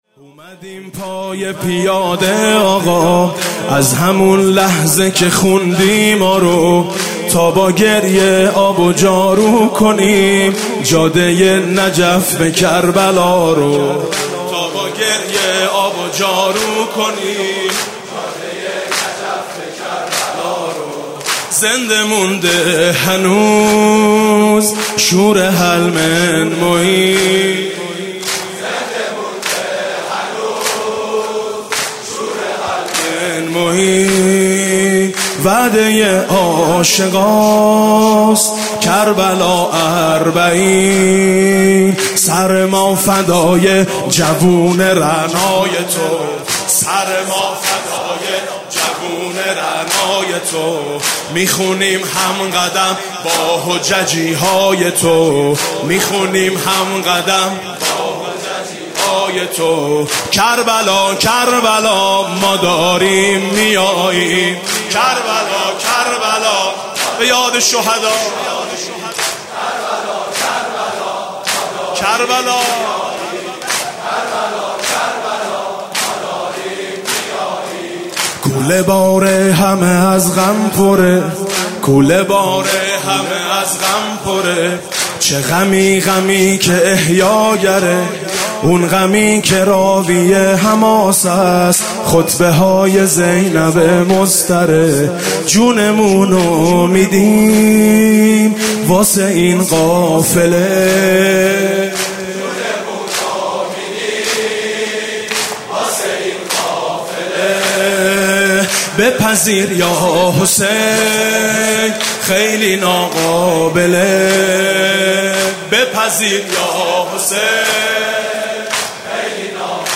جدیدترین مداحی فارسی ـ عربی